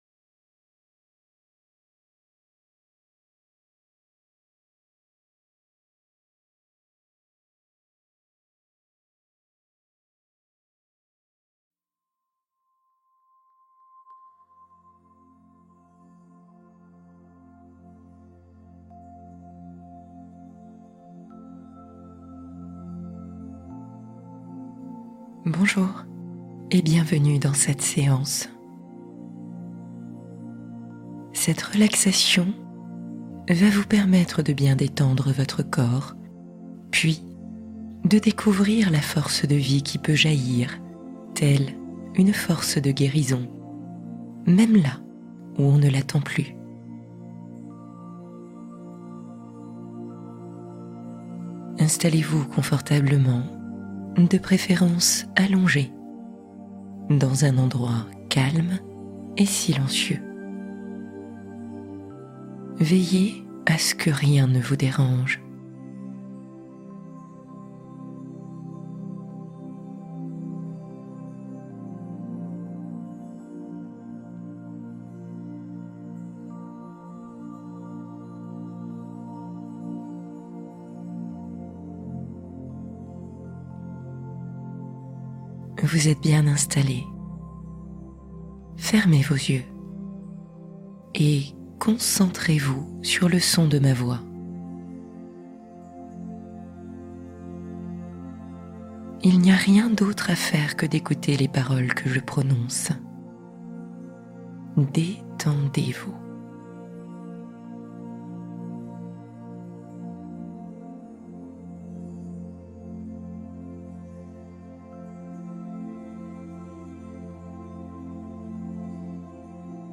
L'Oasis intérieure : Évadez-vous dans votre refuge secret | Méditation relaxation profonde